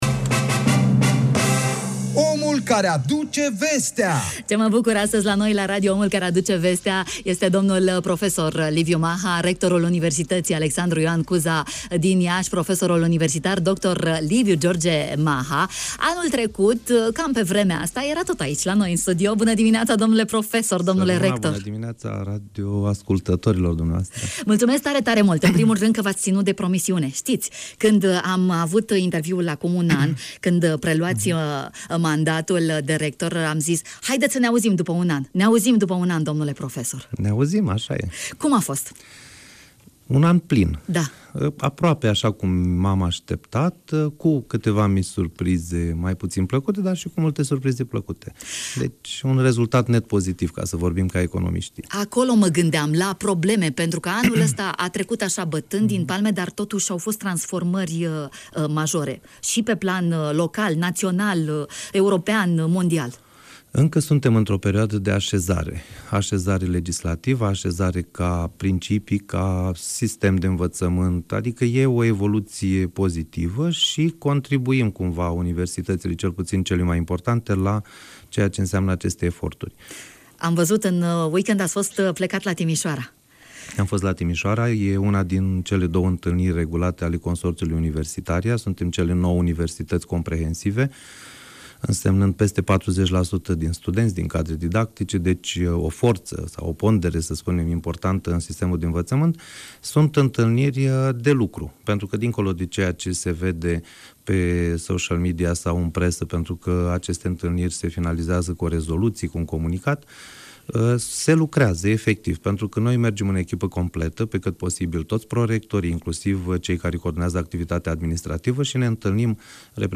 în direct la Bună Dimineața